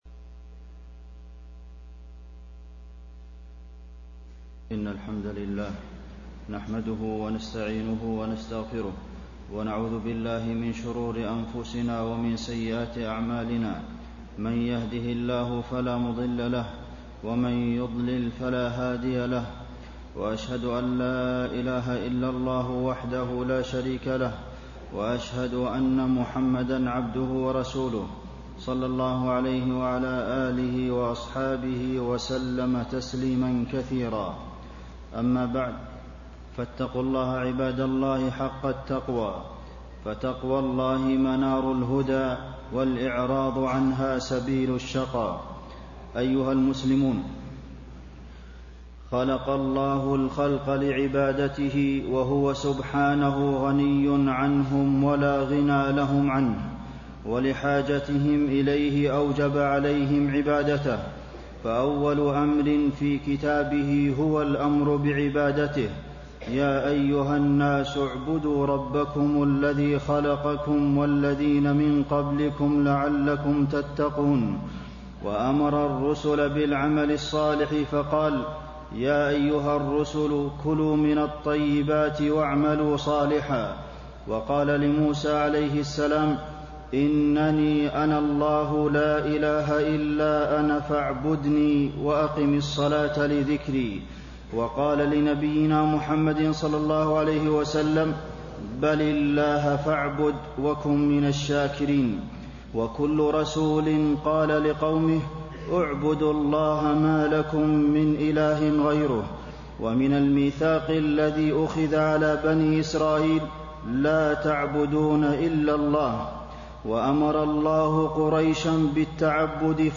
تاريخ النشر ٣ جمادى الأولى ١٤٣٤ هـ المكان: المسجد النبوي الشيخ: فضيلة الشيخ د. عبدالمحسن بن محمد القاسم فضيلة الشيخ د. عبدالمحسن بن محمد القاسم فضائل قيام الليل